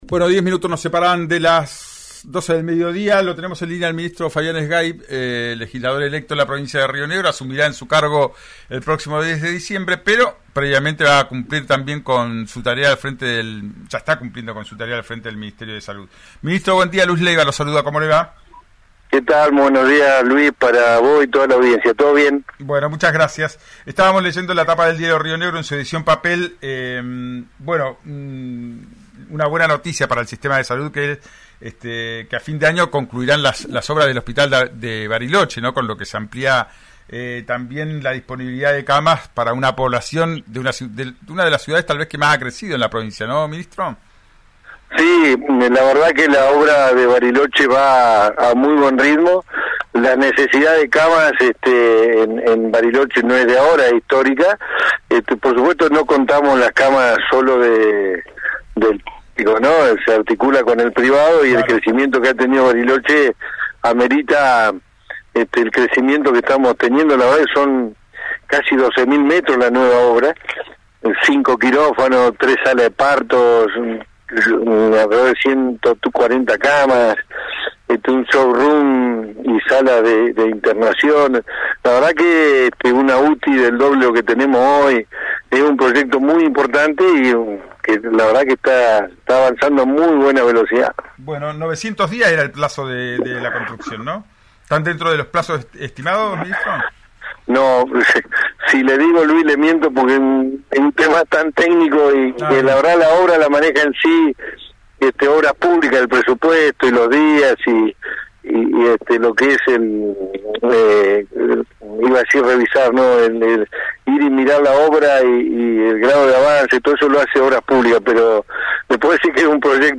Escuchá a Fabián Zgaib, ministro de Salud de Río Negro en «Ya es tiempo» por RÍO NEGRO RADIO: